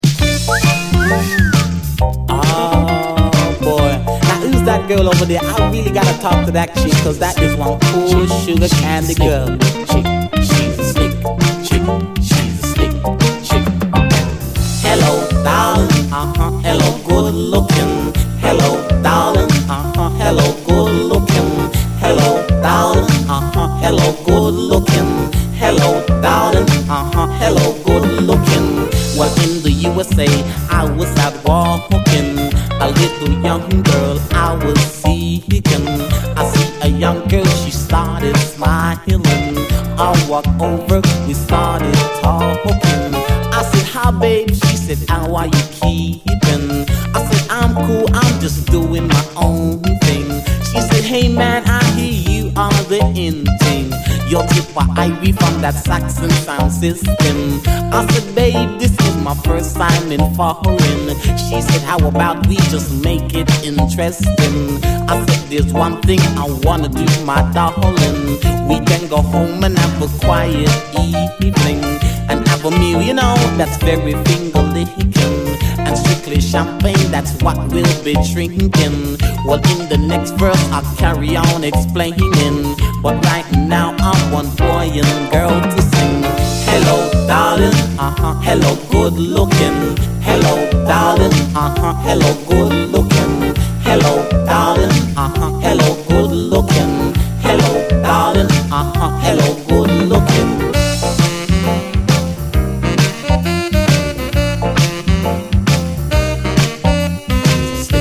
REGGAE
UKラヴリー・ラガ・ポップ・ヒット！UKならではの爽やかでポップなテイスト！愛すべきナイス・チューン！
MADNESSなんかにも通ずる、UKならではの爽やかでポップでヤングなテイスト！ホンワカと心和む、愛すべきイイ曲です！